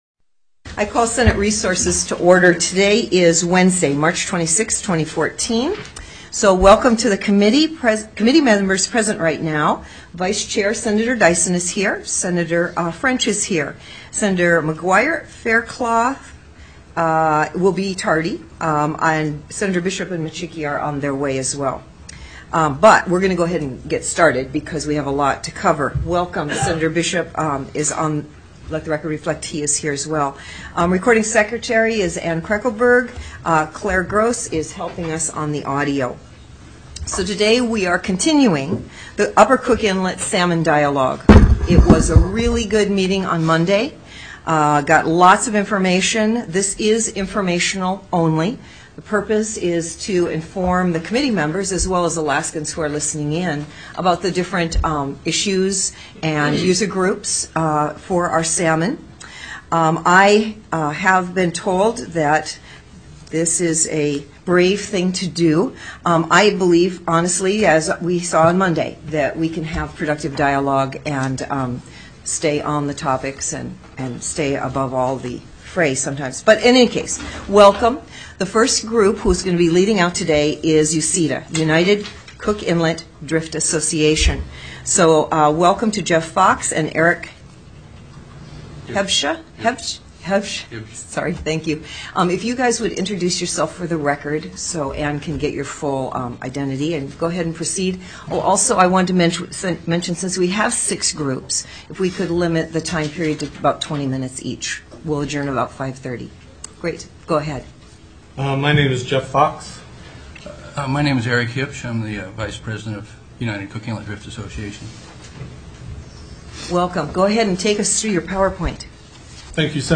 Upper Cook Inlet Salmon Dialogue TELECONFERENCED